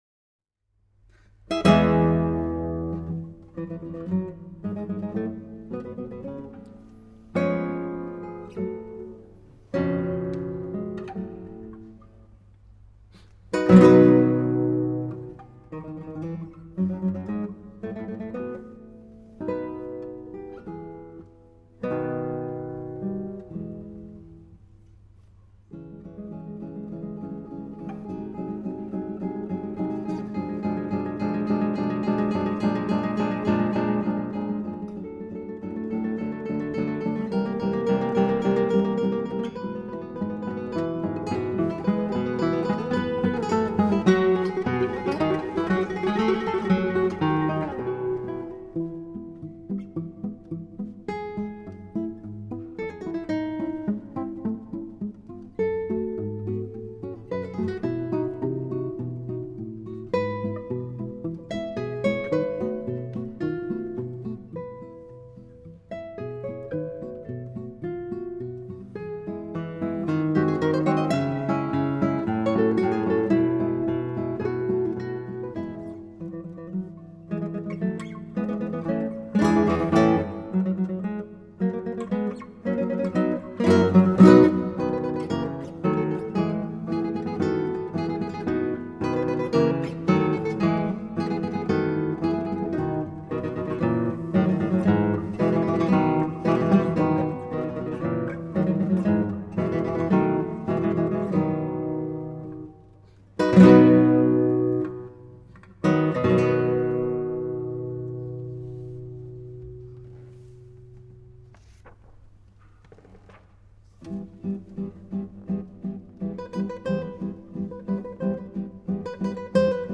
Live 2004-2011
guitar duo